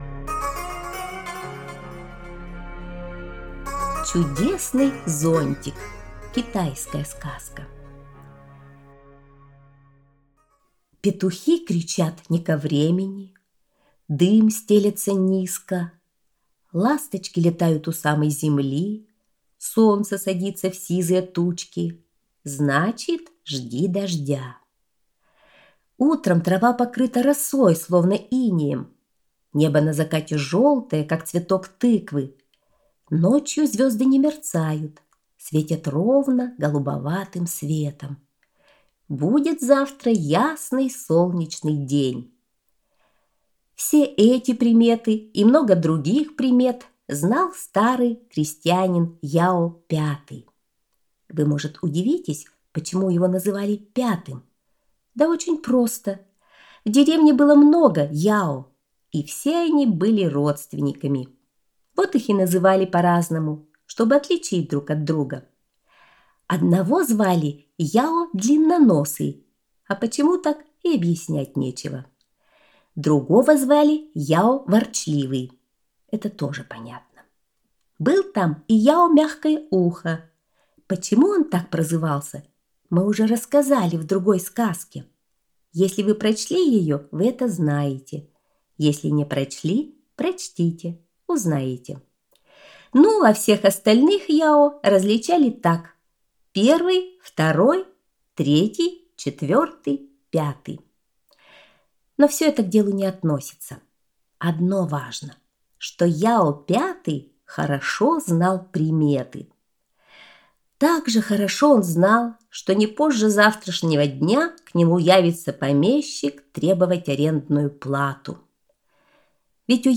Чудесный зонтик – китайская аудиосказка